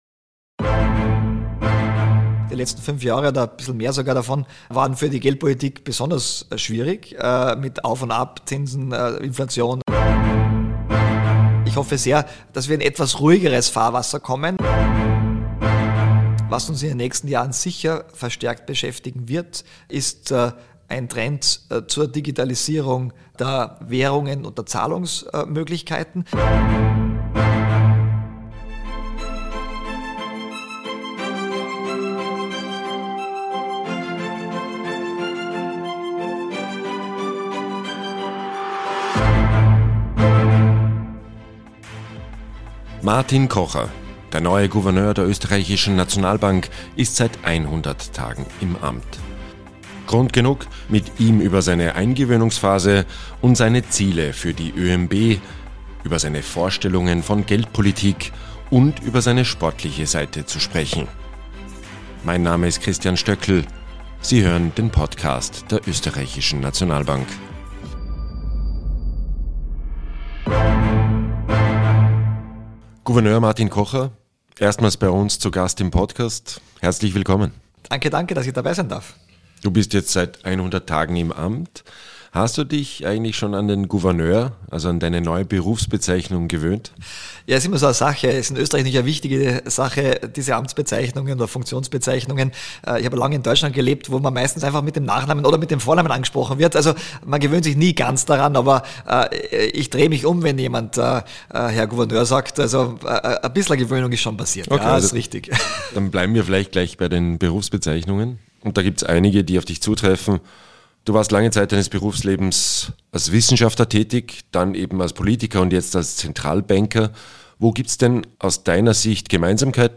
Beschreibung vor 4 Monaten Der neue Gouverneur der Oesterreichischen Nationalbank, Martin Kocher, gibt einen Überblick über seine ersten 100 Tage im Amt. Er spricht über Erwartungen und überraschende Momente, die diese Anfangsphase geprägt haben. Zudem wirft er einen Blick auf die wichtigsten geldpolitischen Herausforderungen der EZB und gewährt auch einen kurzen Einblick in seine private sportliche Seite.